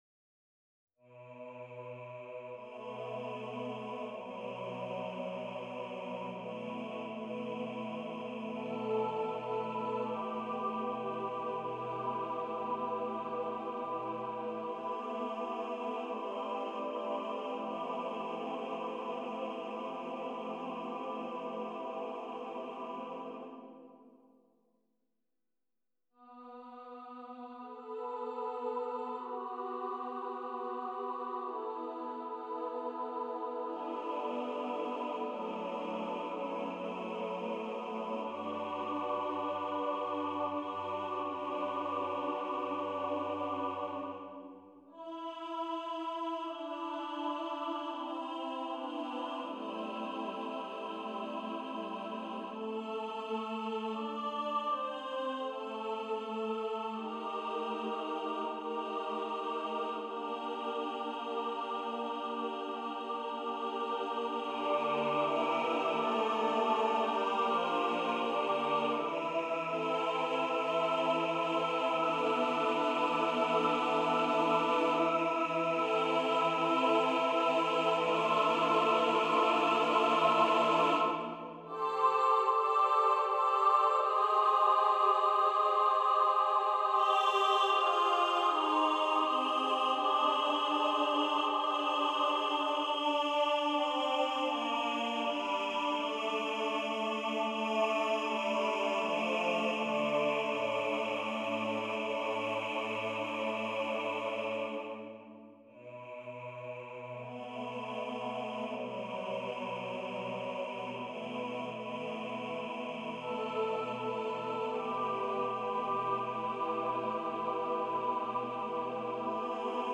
SSAATTBB a cappella